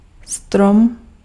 Ääntäminen
Ääntäminen Tuntematon aksentti: IPA: /strɔm/ Haettu sana löytyi näillä lähdekielillä: tšekki Käännös Ääninäyte 1. albero {m} IT Suku: m .